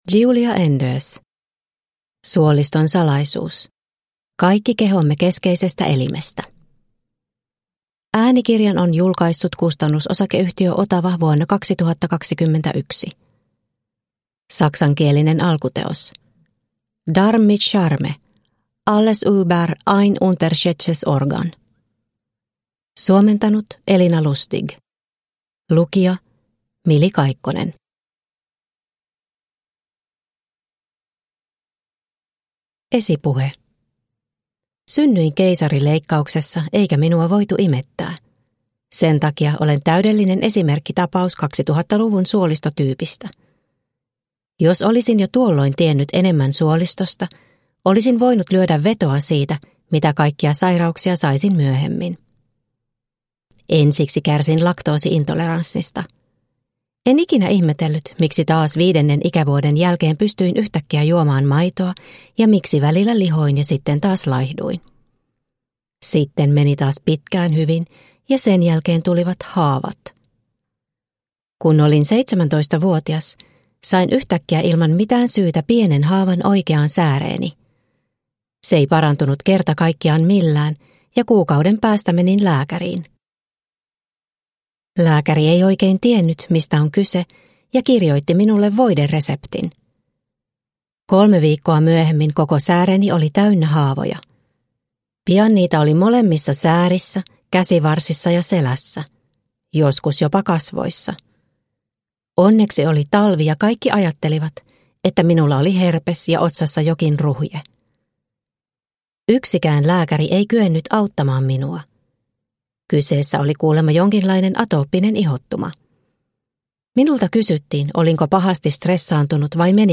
Suoliston salaisuus – Ljudbok – Laddas ner